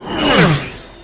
flyby_c.wav